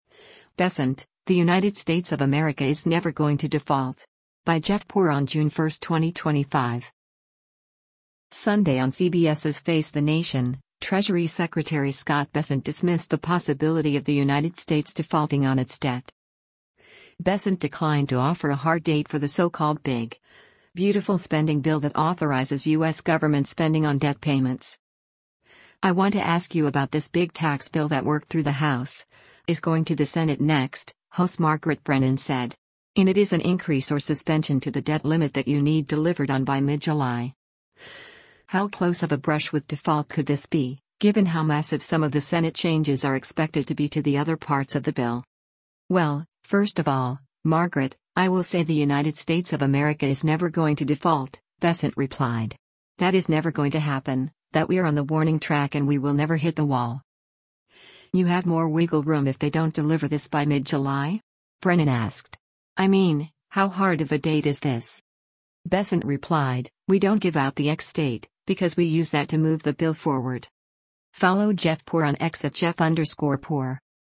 Sunday on CBS’s “Face the Nation,” Treasury Secretary Scott Bessent dismissed the possibility of the United States defaulting on its debt.